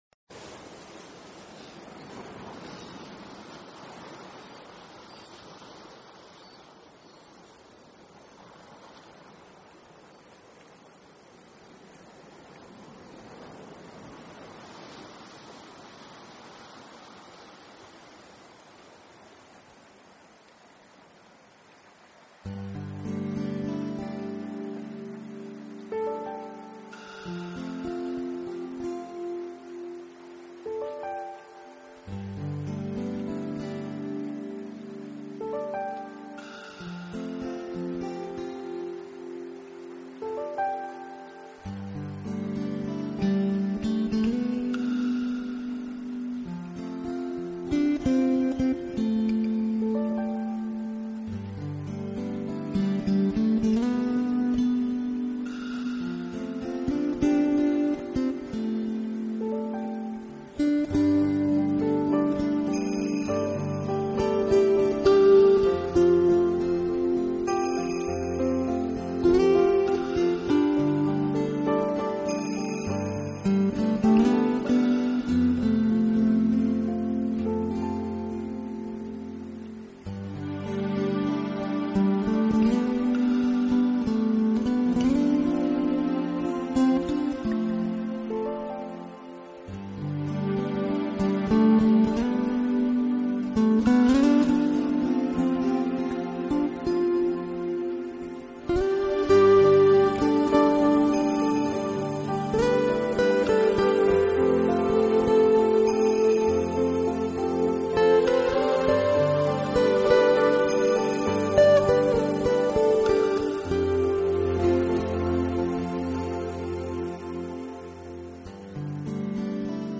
Genre..........: New Age
helps create a relaxing and inspiring atmosphere.